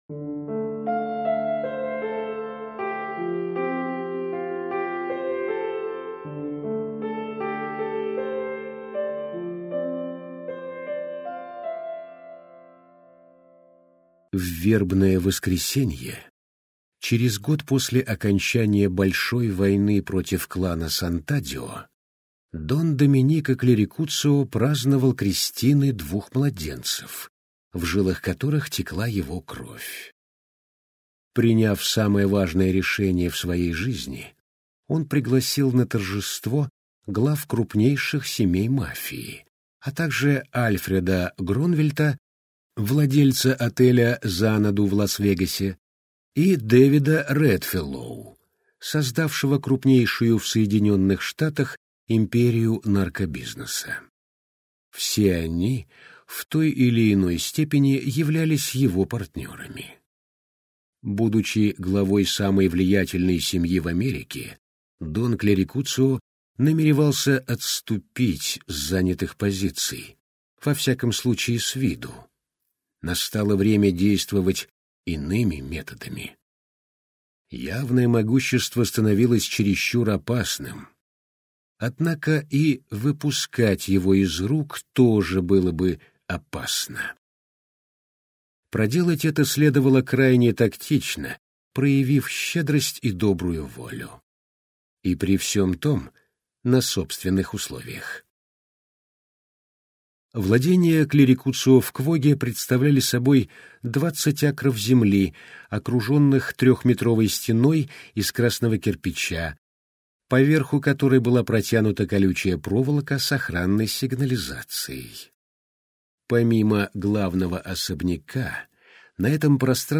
Аудиокнига Последний дон - купить, скачать и слушать онлайн | КнигоПоиск